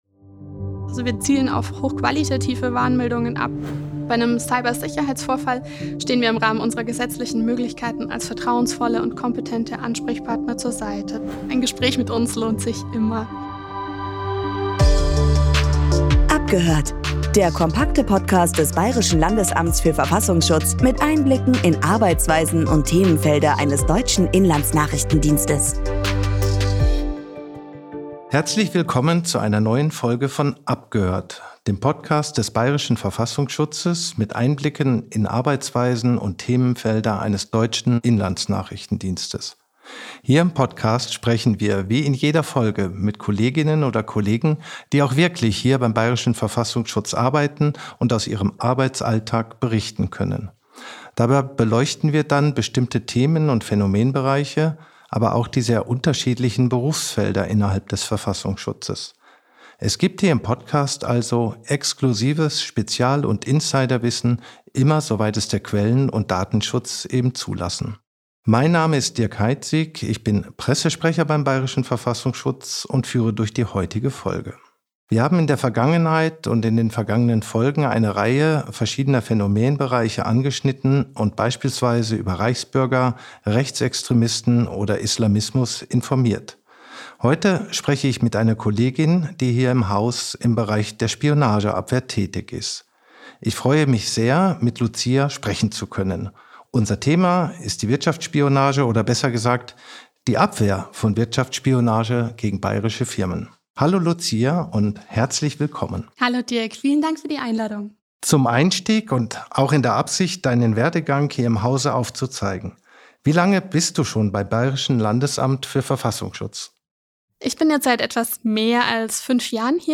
Wir erfahren auch, wie sich Unternehmen und Forschungseinrichtungen konkret schützen können und wie der Wirtschaftsschutz hierbei unterstützen kann. Abgerundet wird die Folge durch ein Beispiel aus der Arbeitspraxis des Wirtschaftsschutzes. Moderation